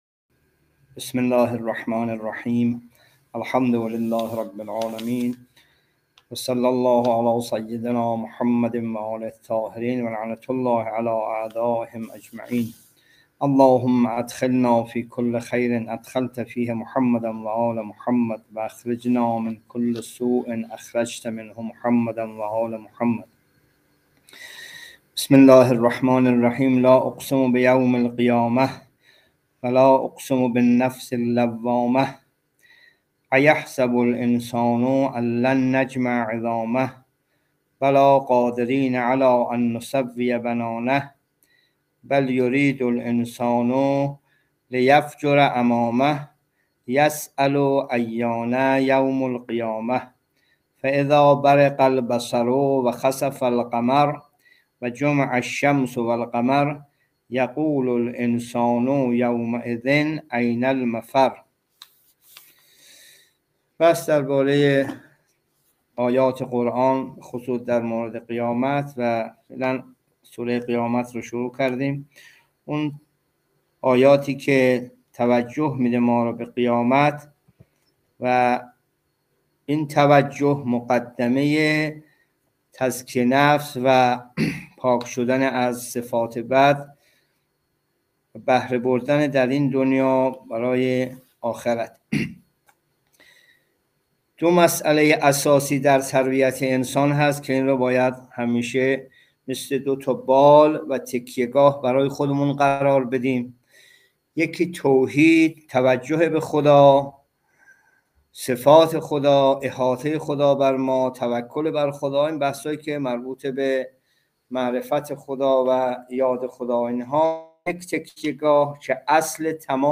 جلسه تفسیر قرآن(۲) سوره قیامت